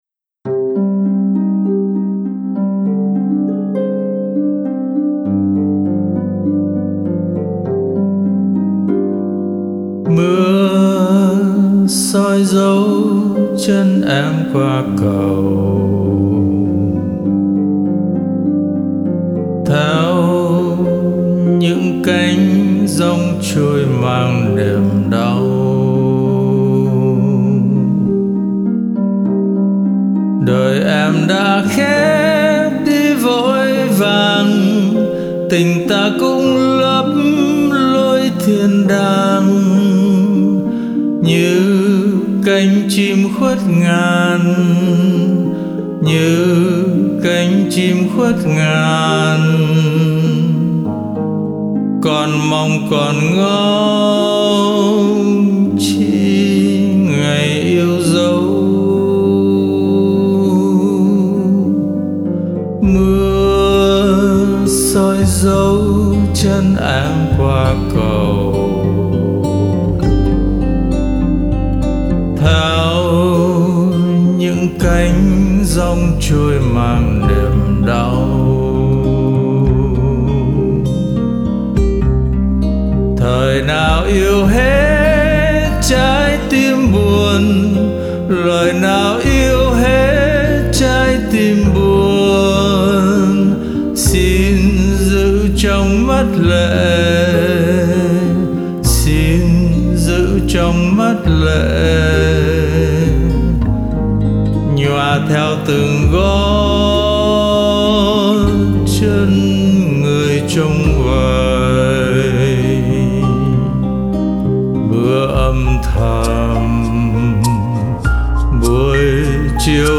Dĩ nhiên theo một phong cách khác.  Với một giọng hát khác.